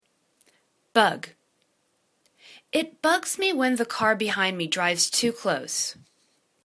bug     /bug/    v